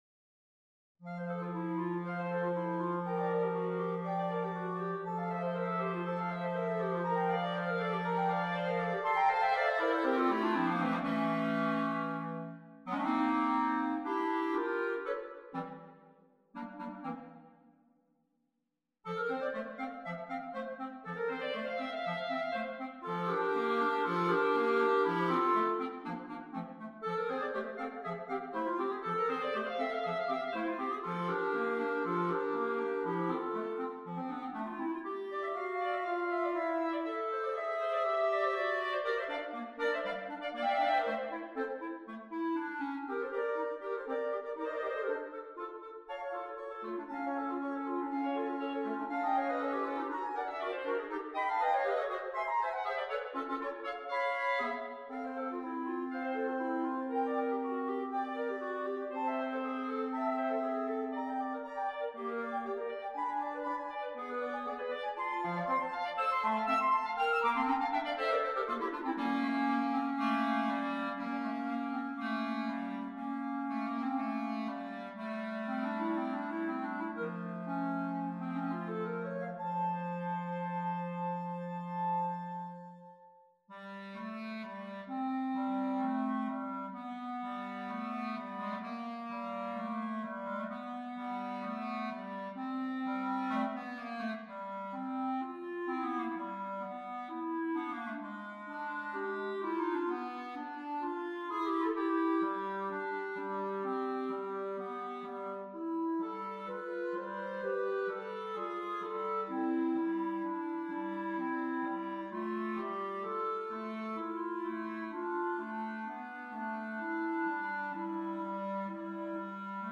for three clarinets